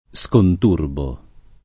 conturbo [ kont 2 rbo ]